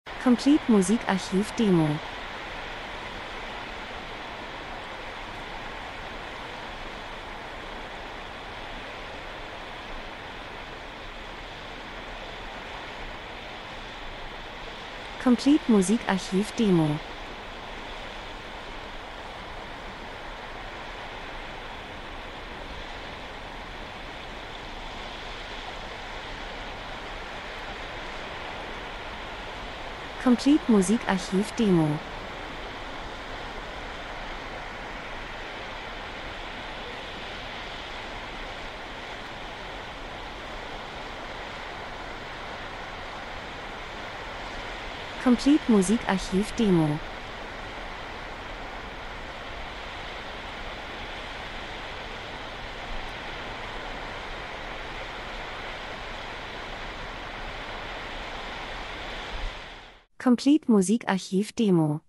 Herbst -Geräusche Soundeffekt Wind Regen Wald 01:01